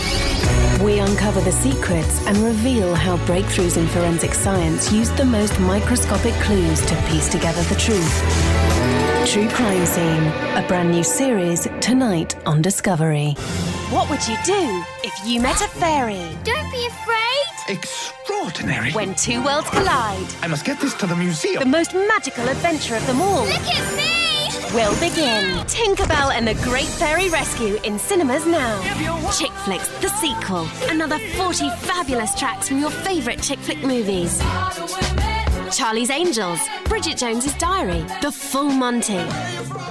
Promo Reel
RP ('Received Pronunciation')
Promo, Cool, Energetic, Confident, Fun, Friendly